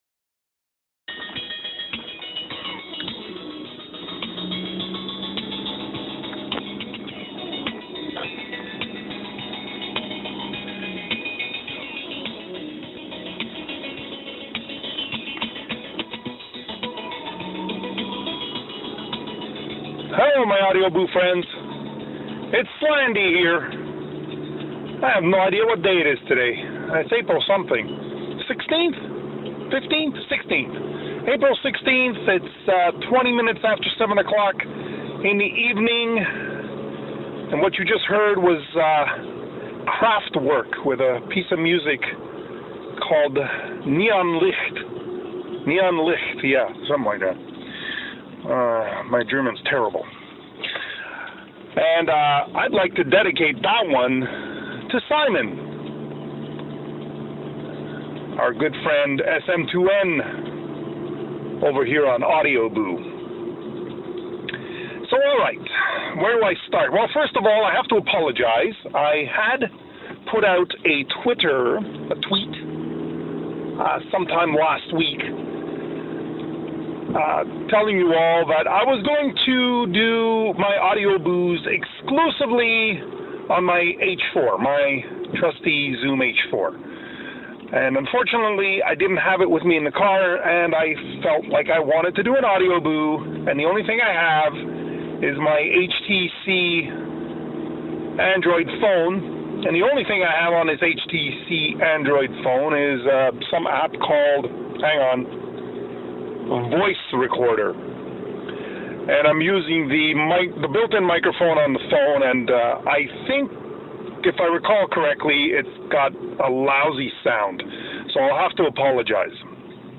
A catch up boo on my iPhone 3GS Status, sorry about the lousy sound